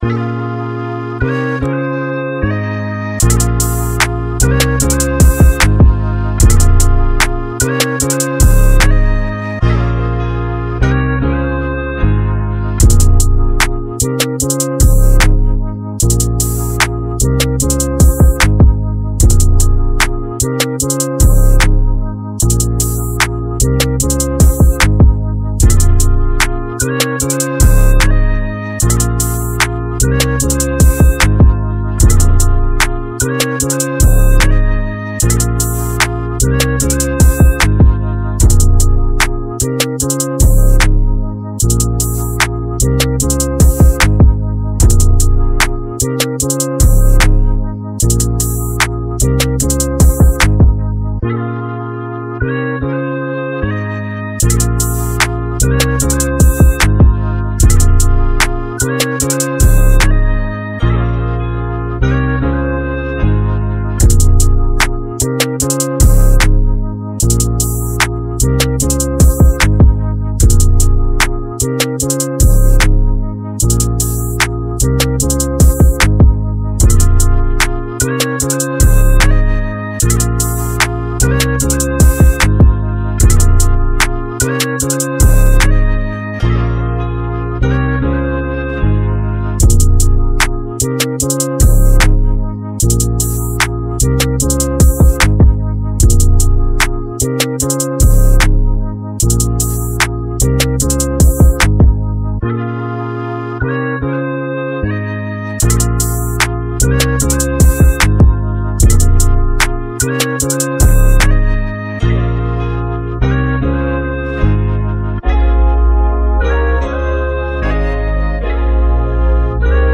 Hip hop hiphop trap beats
rap beats